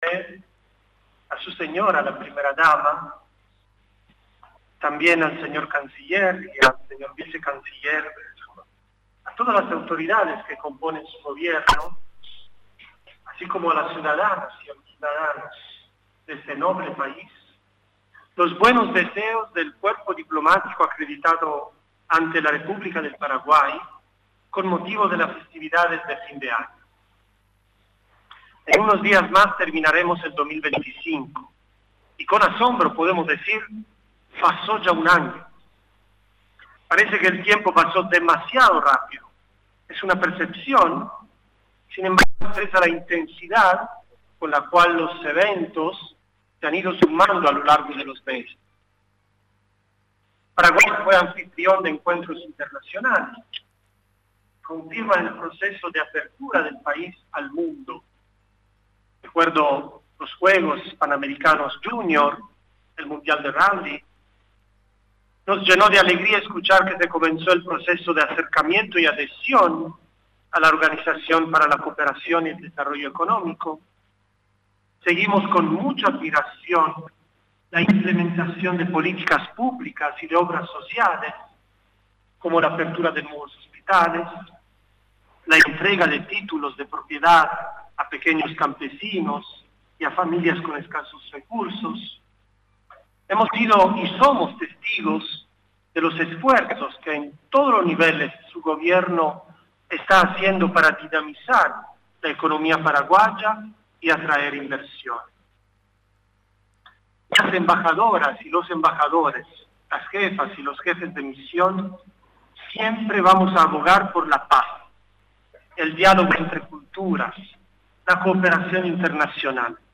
En un acto, realizado este lunes en el Salón Independencia del Palacio de Gobierno, el cuerpo diplomático acreditado ante el Paraguay, presentó el saludo protocolar al presidente de la República, Santiago Peña, ocasión en que manifestó su reconocimiento a los esfuerzos del Gobierno tanto en una política exterior que atraiga inversiones, así como a las políticas públicas implementadas para mejorar la calidad de vida del pueblo paraguayo.